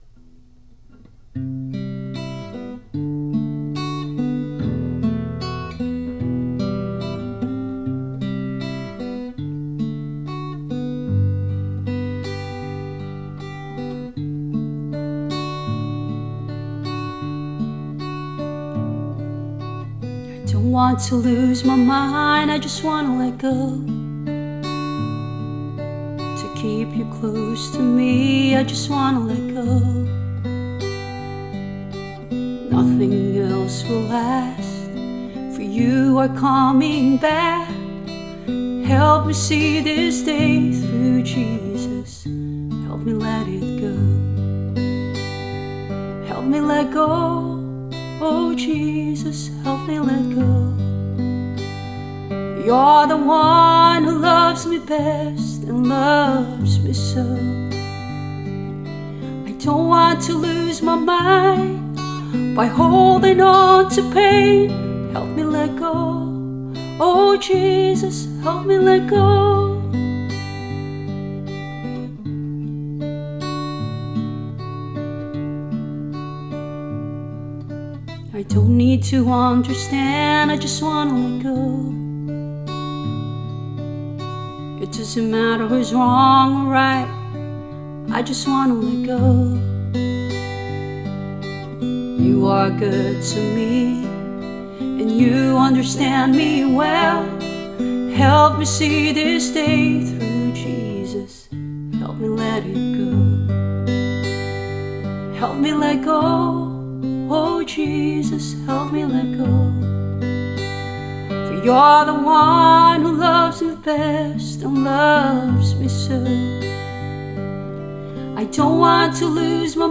Voice and Guitar